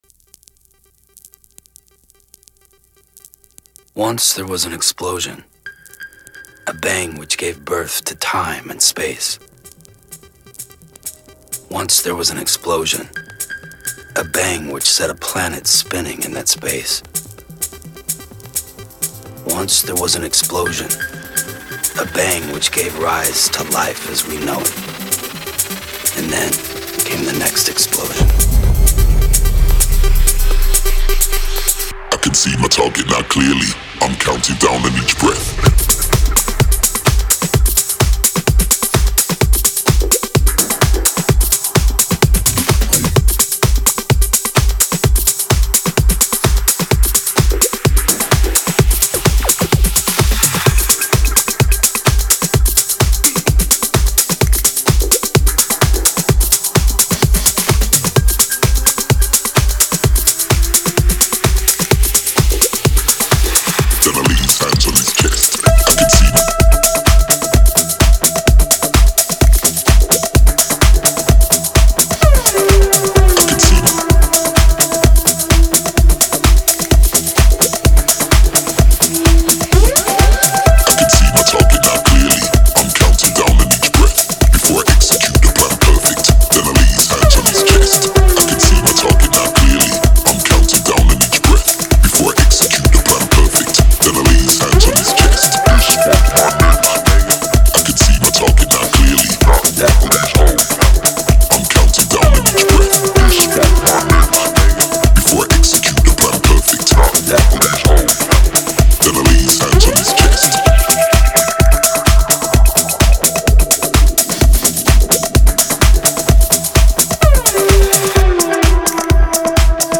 Genre: EDM. https